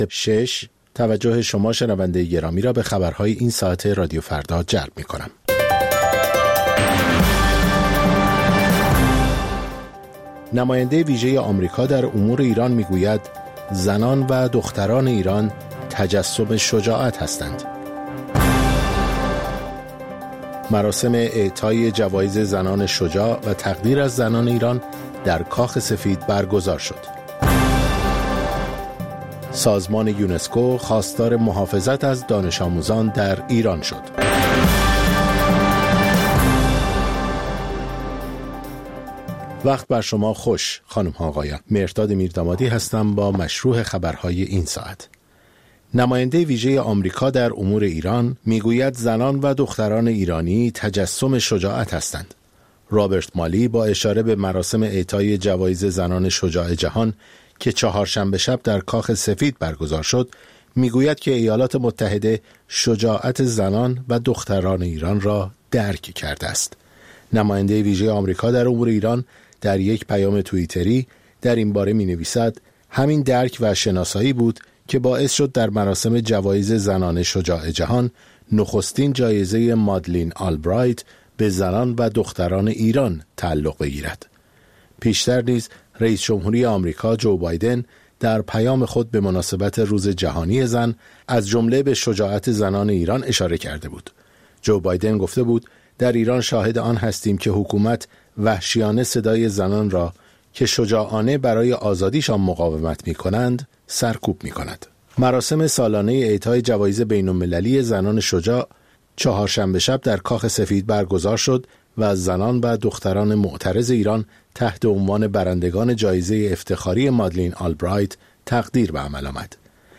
سرخط خبرها ۶:۰۰